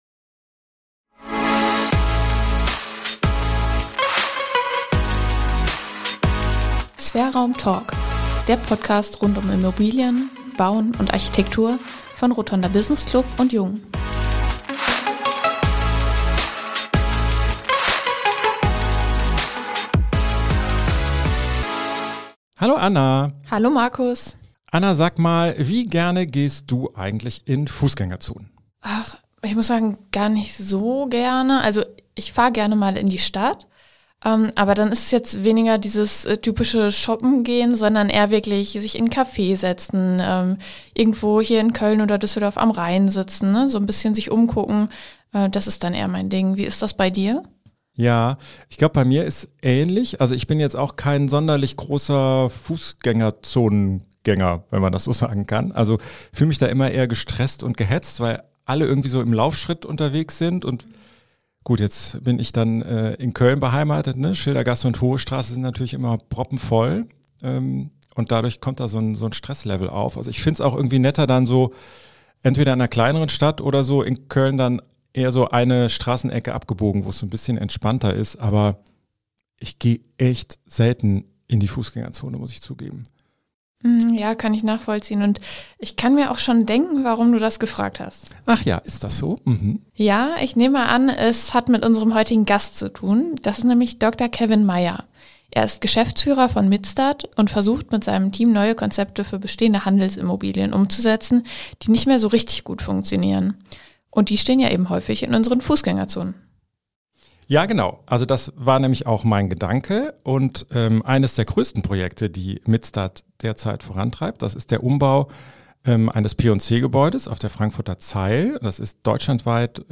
Ein Gespräch über die Zukunft der Innenstadt, die zunehmende Bedeutung von Betreiberkonzepten für Immobilien und den großen Vorteil von Digitalisierung und Innovationen.